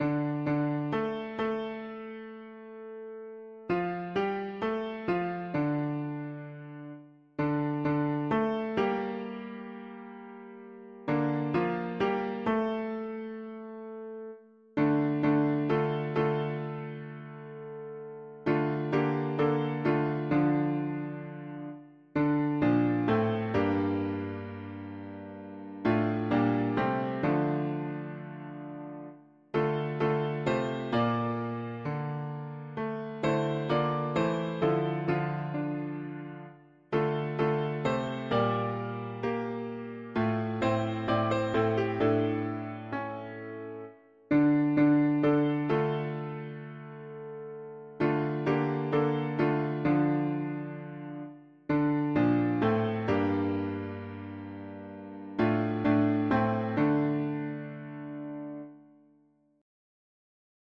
I’m g… english christian 4part
Music: American traditional
Key: D minor